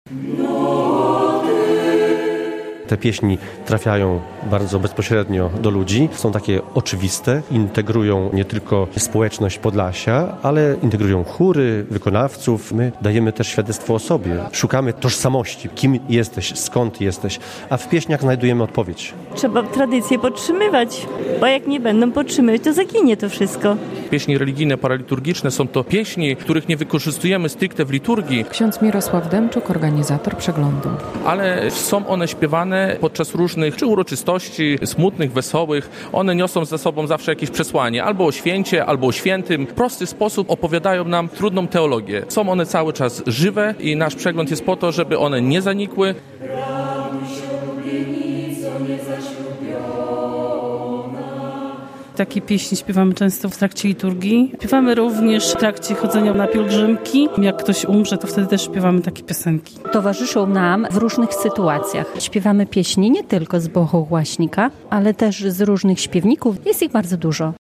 Pieśni ludowe, melodie monasterskie, ale też współczesnych kompozytorów wybrzmiały w niedzielę (17 11) w cerkwi p.w. Zmartwychwstania Pańskiego w Siemiatyczach.
Międzynarodowy Przegląd Pieśni Religijnej i Paraliturgicznej w Siemiatyczach - relacja